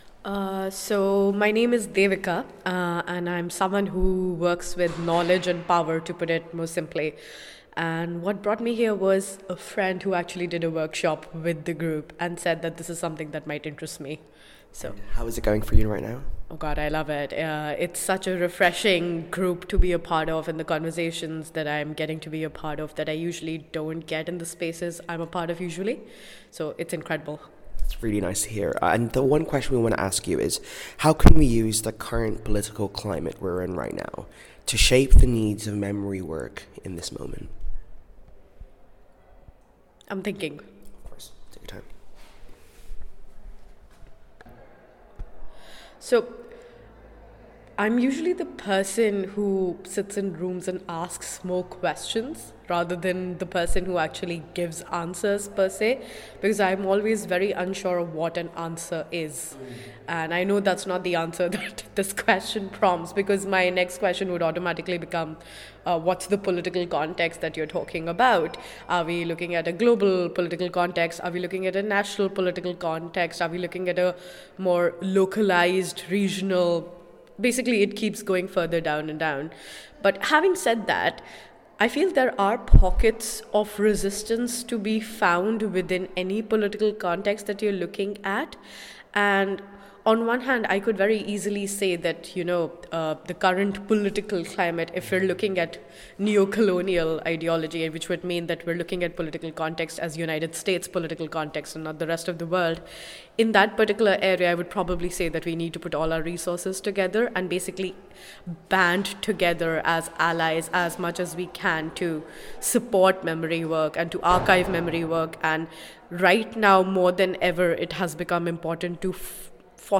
The following audio reflections, recorded during the Brighton convening, feature members of the UK Community of Practice addressing the urgent question: How can we use the current political climate we’re in right now to shape the needs of memory work?